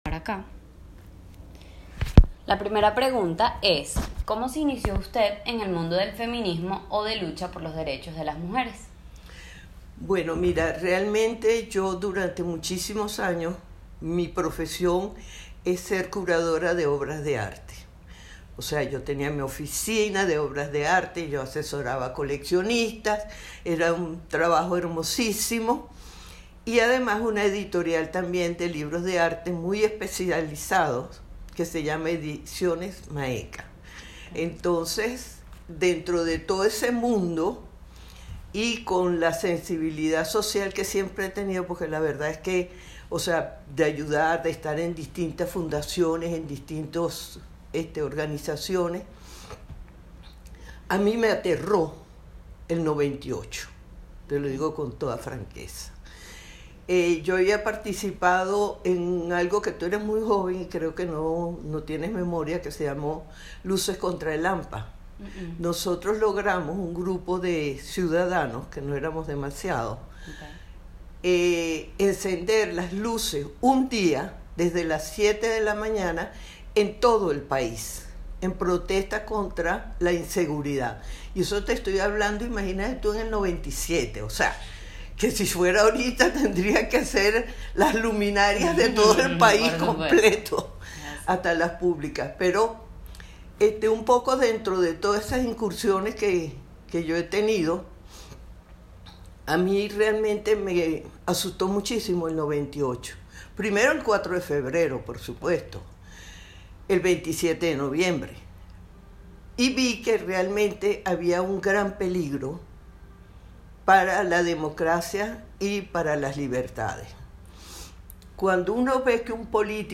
Oigamos su testimonio de viva voz